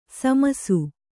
♪ samasu